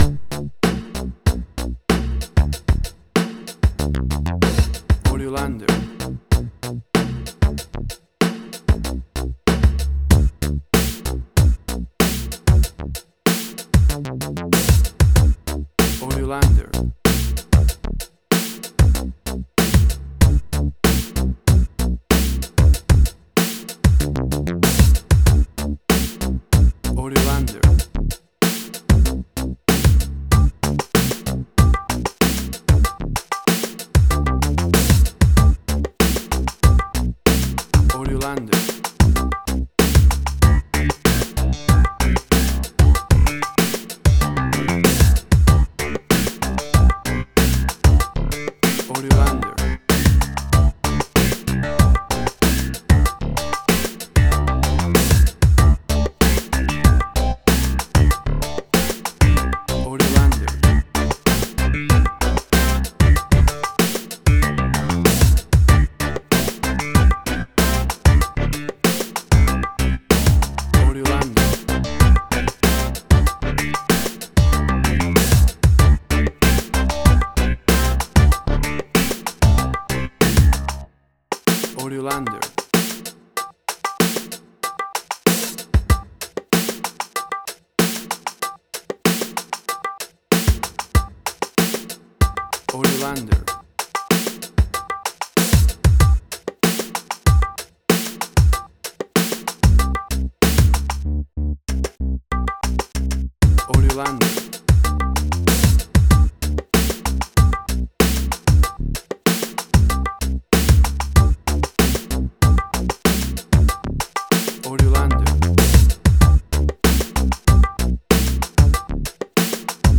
A seventies based funk track
Tempo (BPM): 95